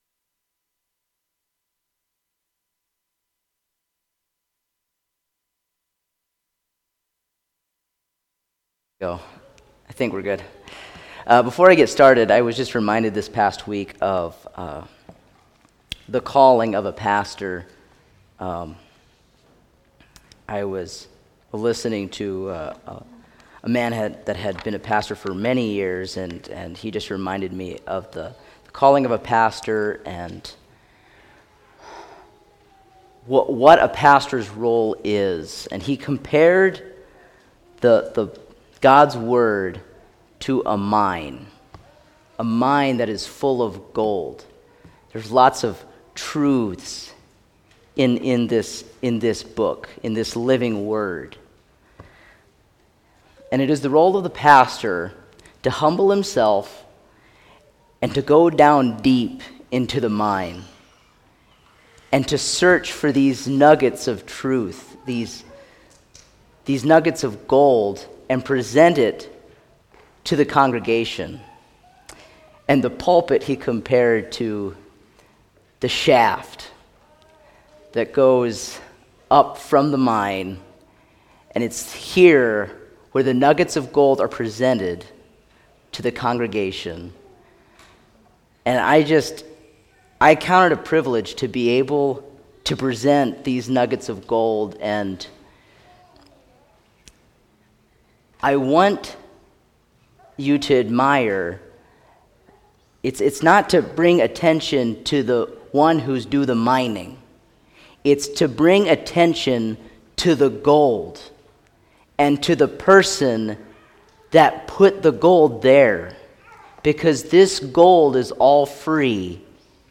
Home Sermons But God…